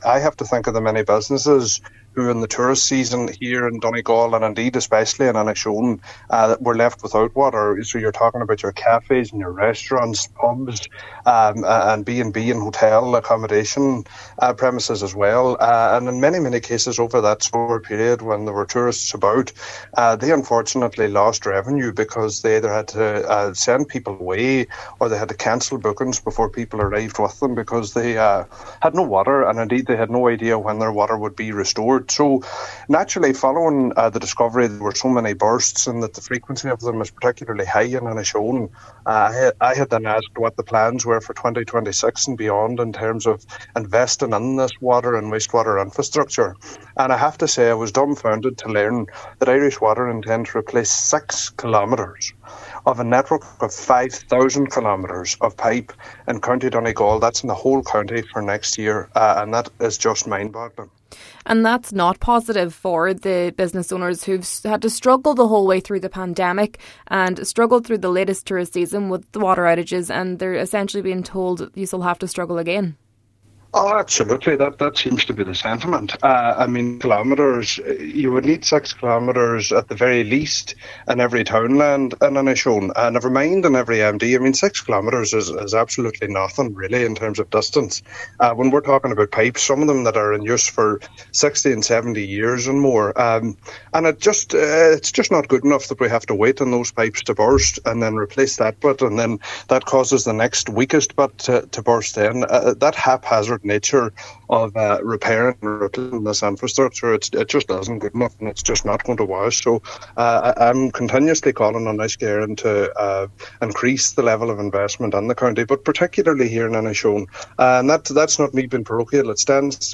Cllr Bradley says the knock-on effect of this has been felt in the area, especially by those working in the tourism industry, who are now left to struggle for another year: